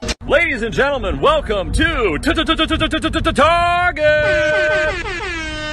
PLAY call of duty target acquired sound effect